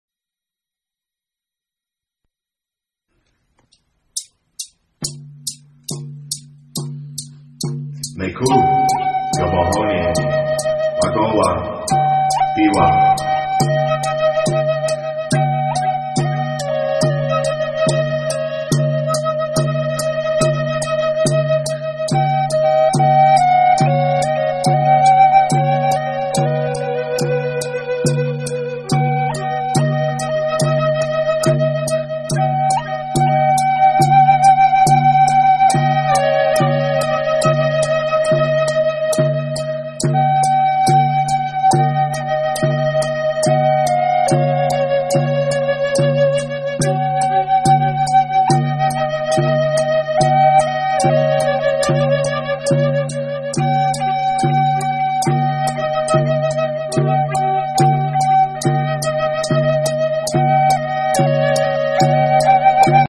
Native American Flute Music and More